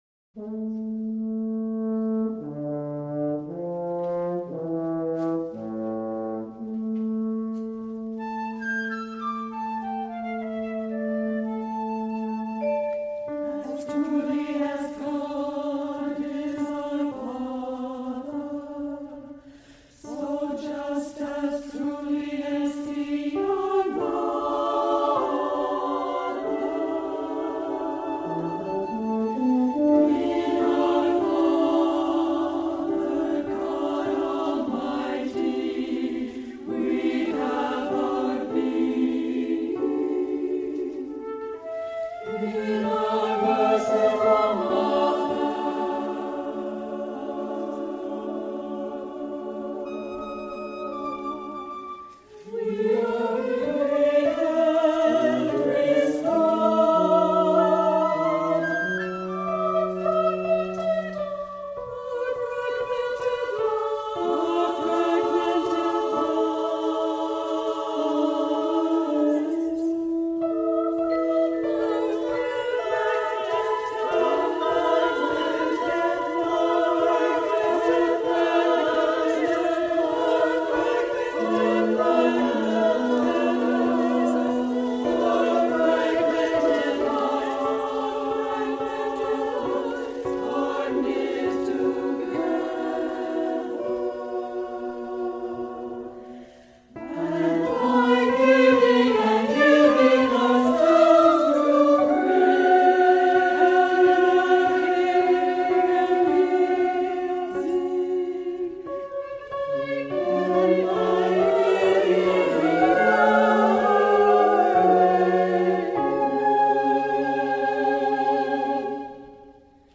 SSA (3 voices women) ; Full score.
Sacred. Choir.